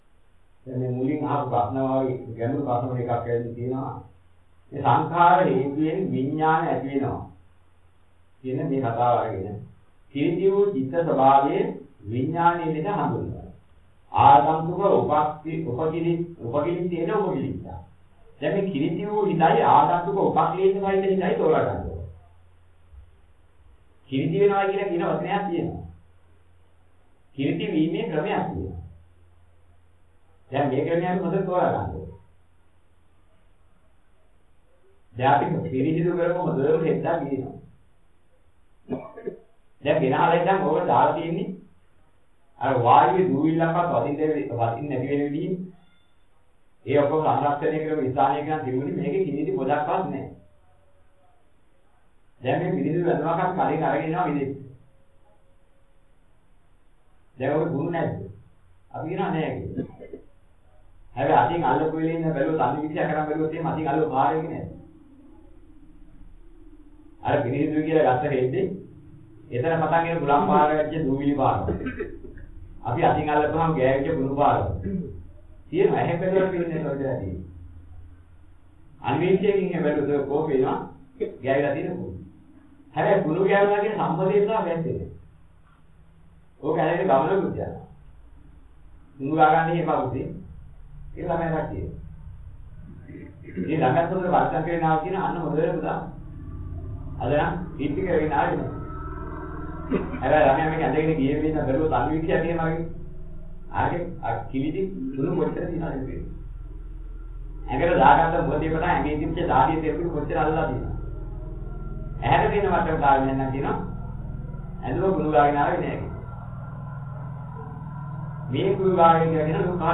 apps සියලුම තැටි  සදහම් සාකච්ඡා-01 • 08    ?